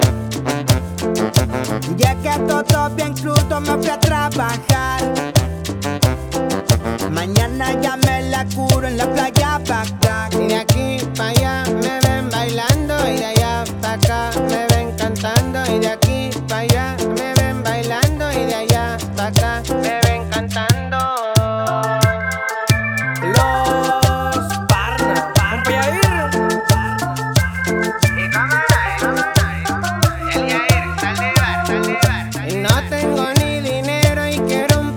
Рингтоны
Жанр: Фолк-рок